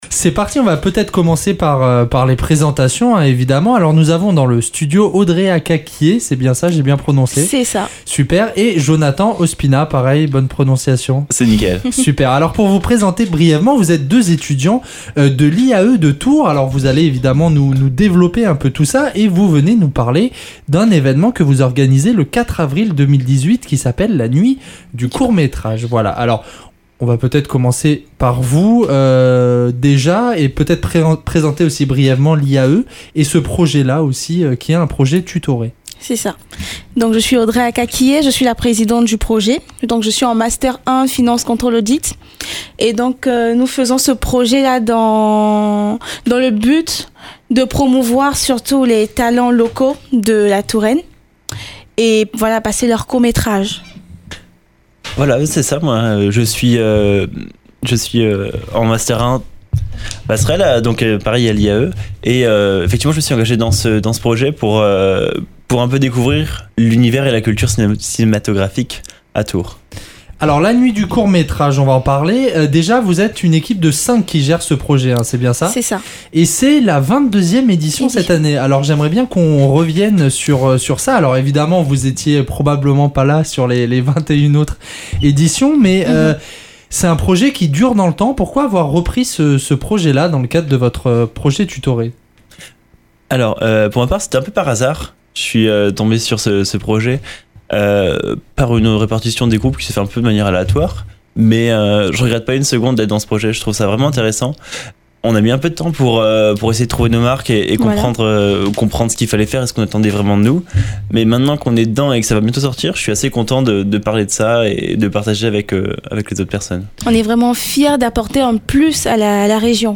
Interview !